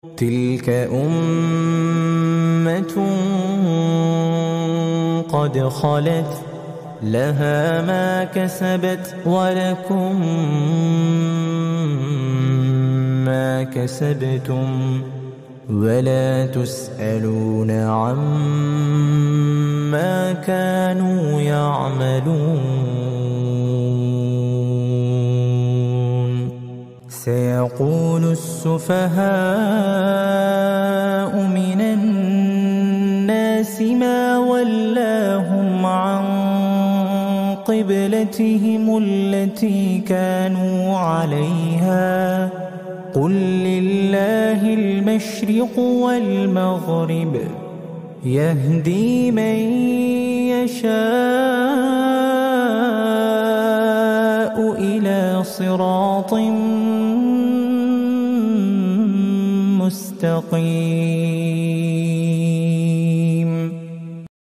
Murottal penyejuk hati Al Qur'an